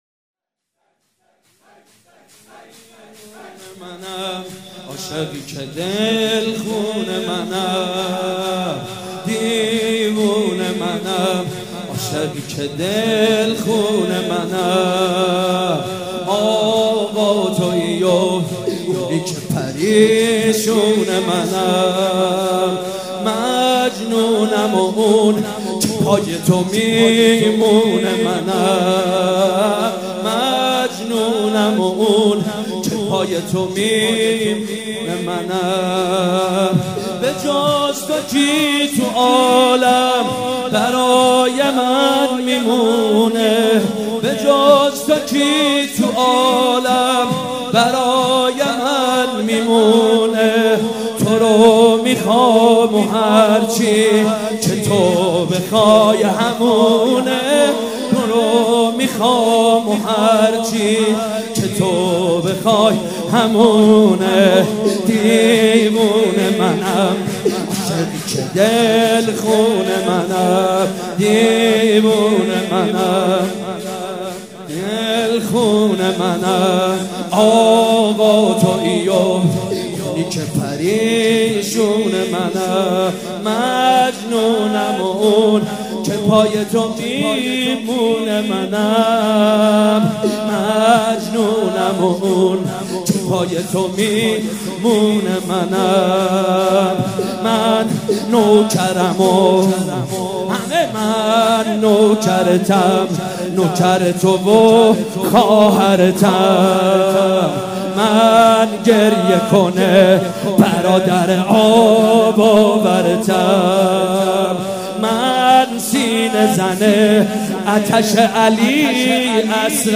شب دوم محرم الحرام‌ دوشنبه ۱۲ مهرماه ۱۳۹۵ هيئت ريحانة الحسين(س)
سبک اثــر شور